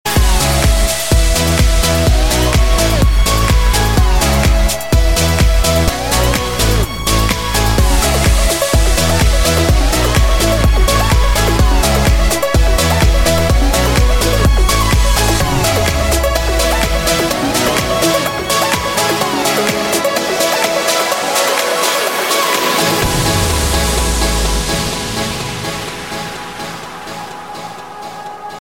Kategorien: Elektronische